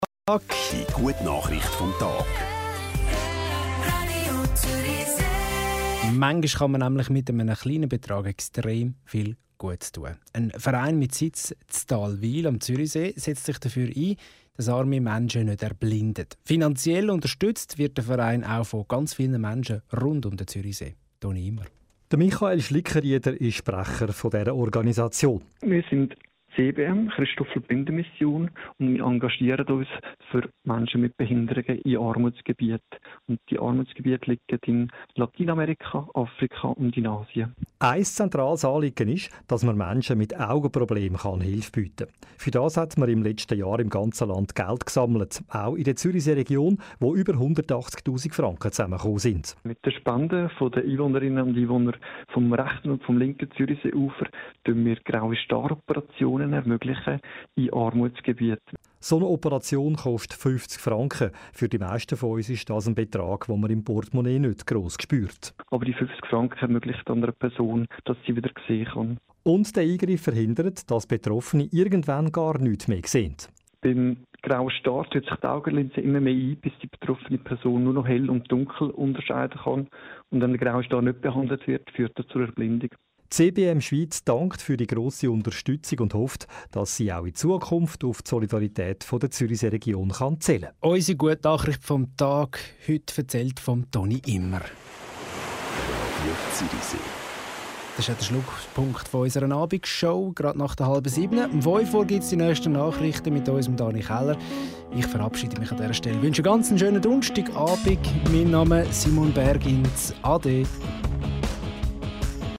Ein Kurzinterview
Zum Radiobeitrag